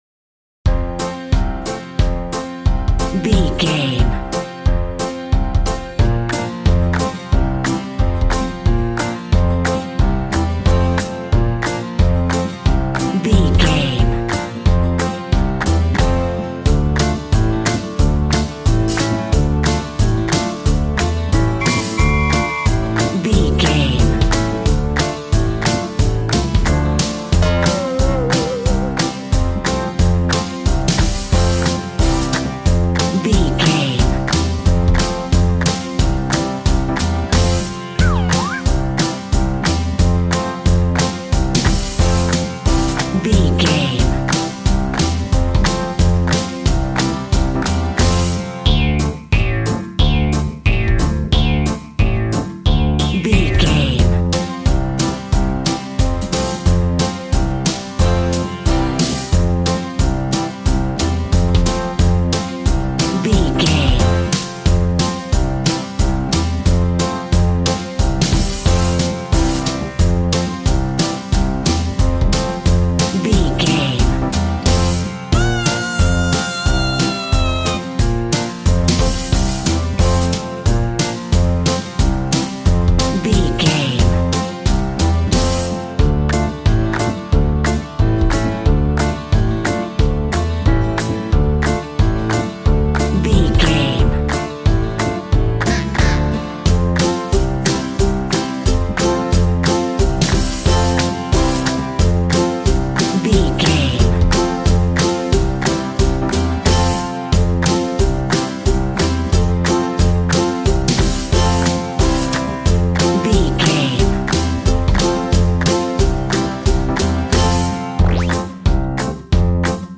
Ionian/Major
cheerful/happy
bouncy
electric piano
electric guitar
drum machine